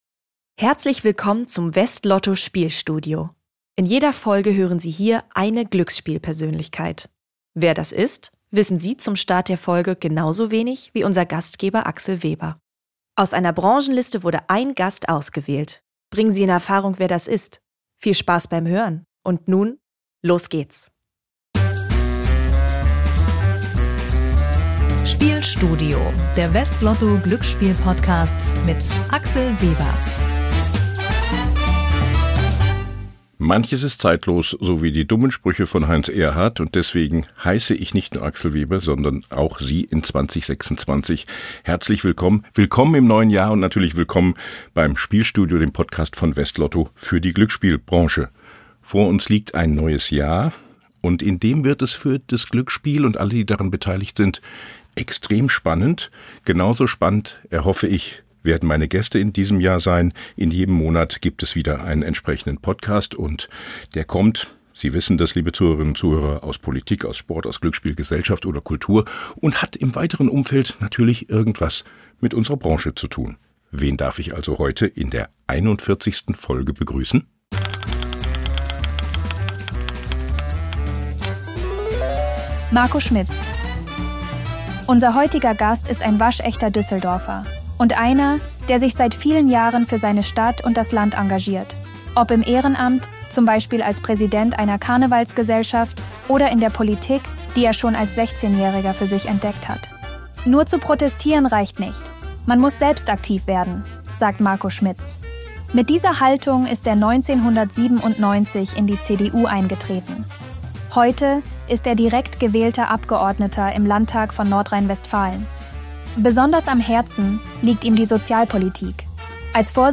Ein Gespräch darüber, was Politik leisten muss, um Demokratie und Zusammenhalt zu stärken.
Hinweis: Ein Zuspieler in diesem Podcast wurde mit einer künstlichen Stimme erzeugt, die von einem KI-System erstellt wurde.